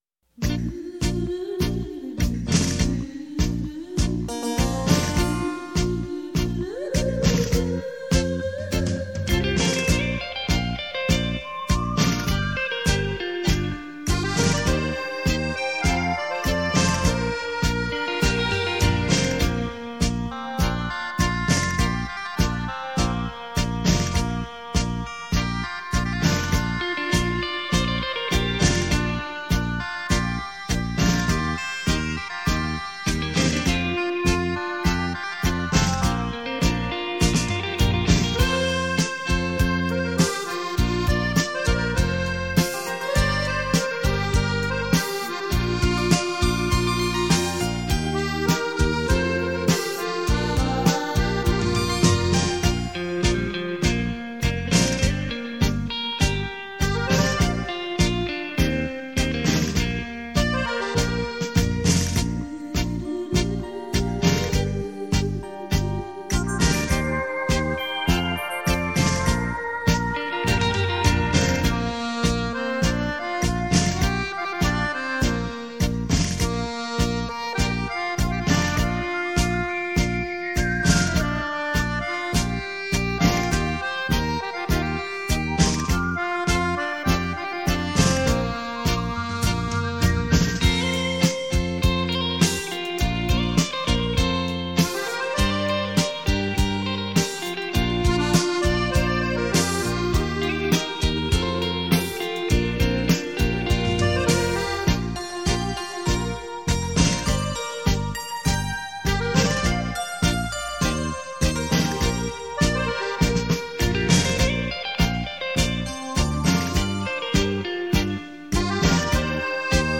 身历其境的临场效果
享受音乐的洗礼身历其境的临场音效